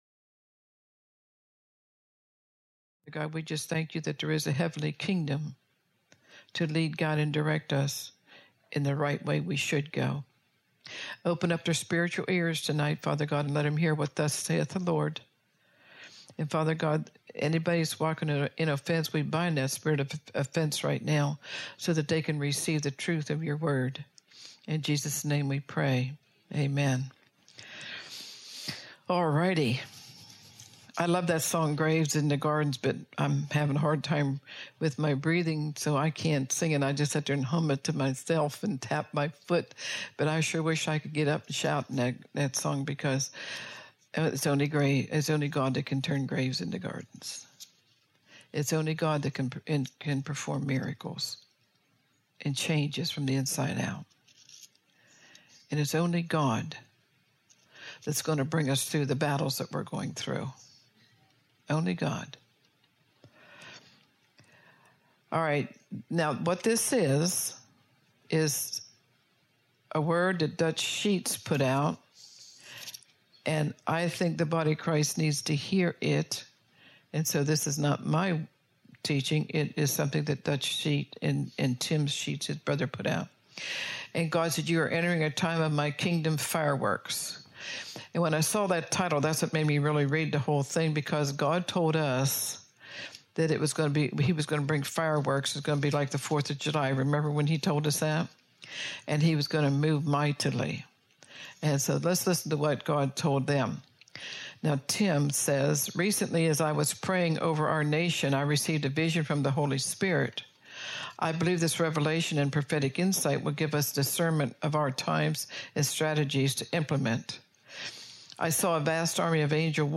Posted in Sermons